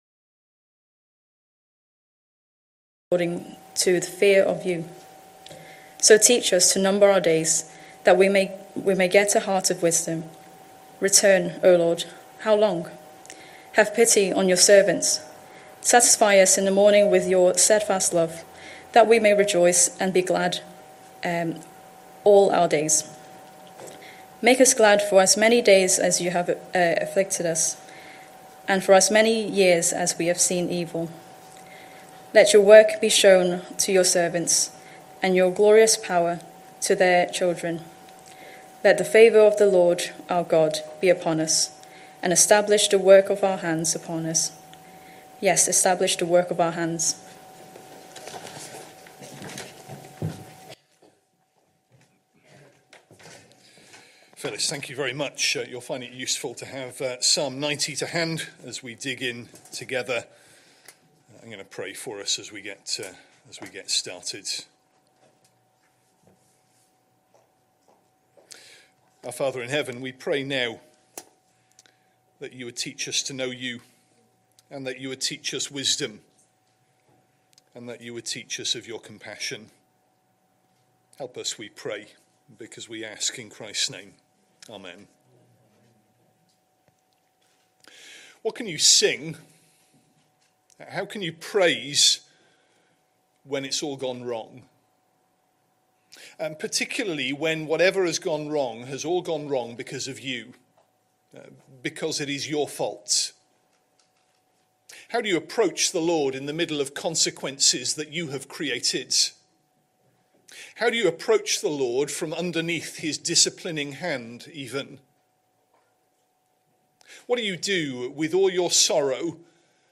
Sunday Evening Service Sunday 6th July 2025 Speaker